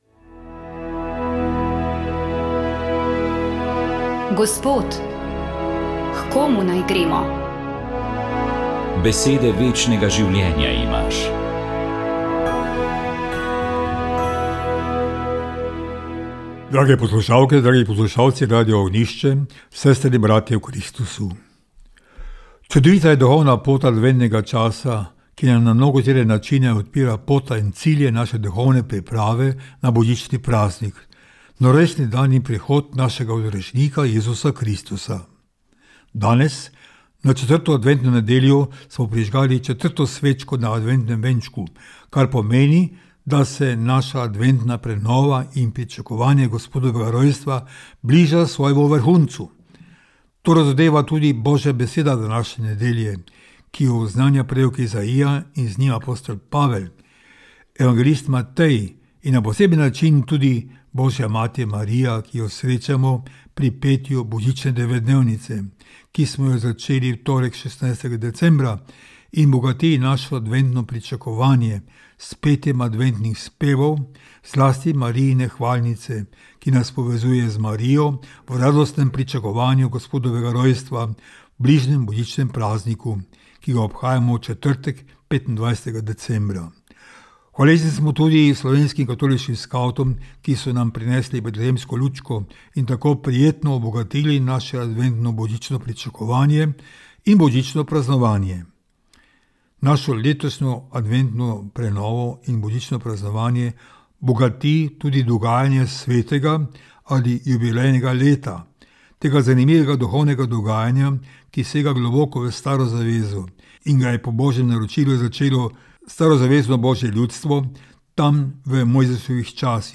Duhovni nagovor
Za praznik Svete Trojice je duhovni nagovor pripravil ljubljanski nadškof msgr. dr. Anton Stres.